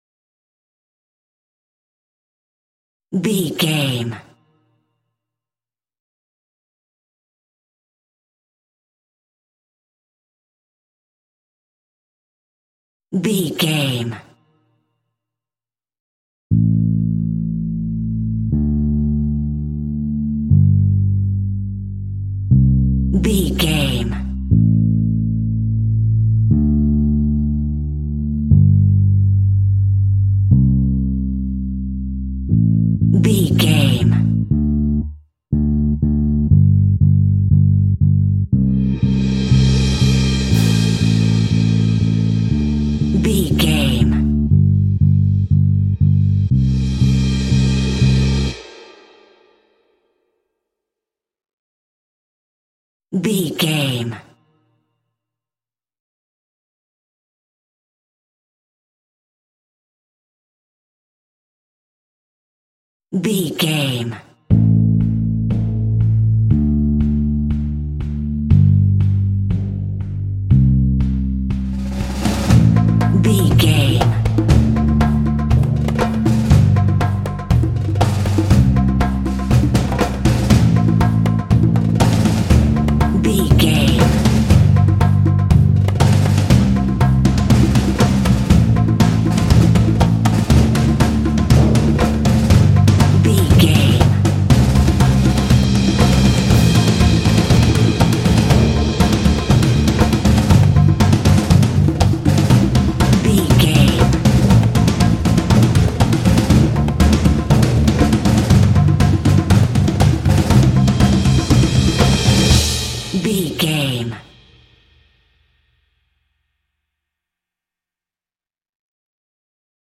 Epic / Action
Fast paced
Ionian/Major
driving
powerful
dreamy
bass guitar
percussion
cinematic
film score
classical guitar